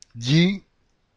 The sounds of flat syllables using the letters LL and Y have no difference to the ear. That is, both letters form syllables that sound the same:
yi-lli.mp3